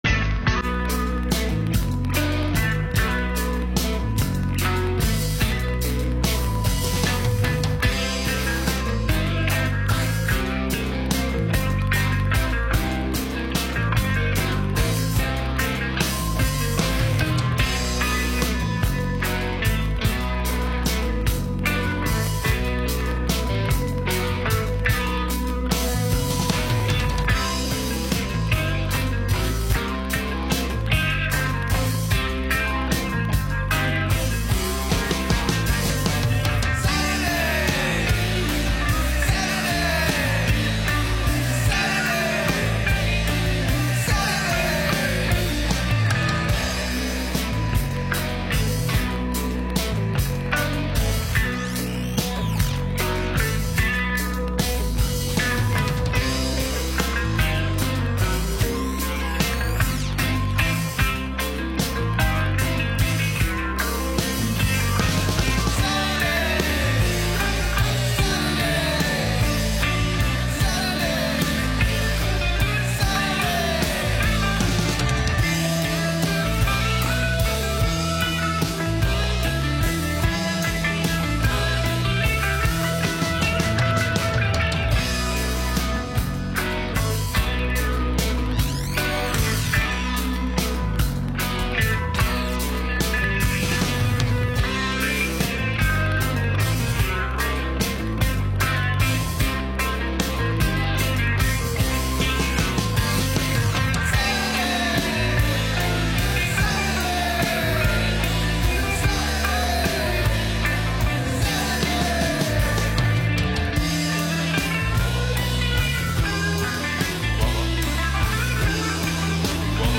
These guys are also playing live in the studio for us, and it sounded great.